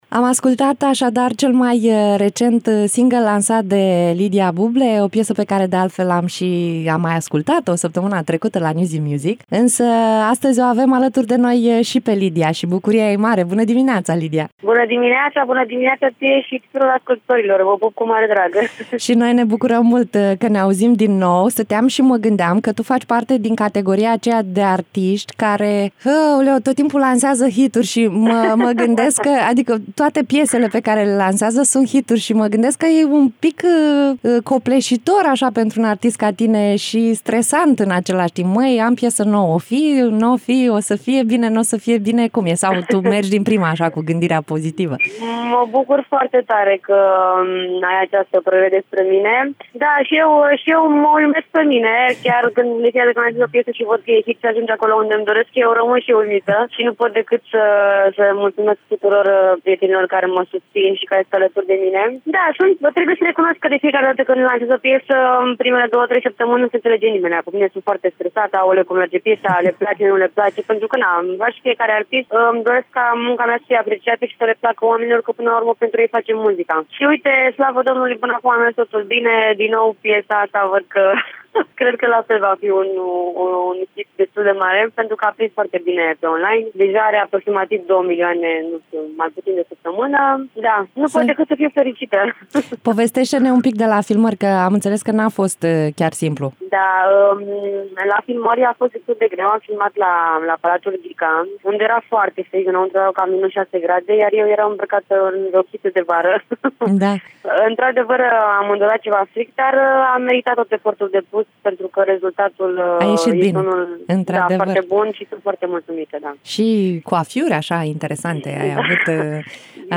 Varianta audio a interviului: